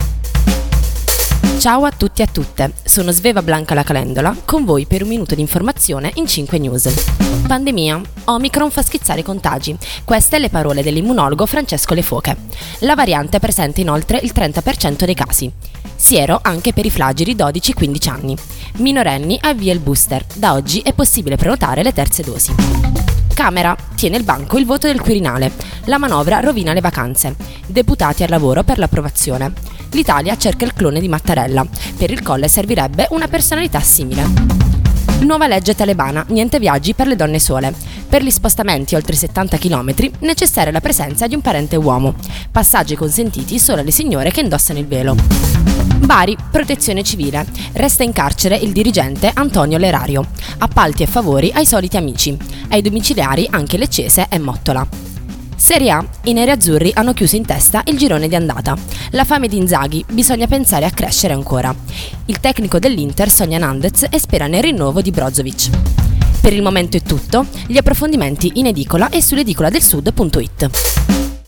Giornale radio alle ore 7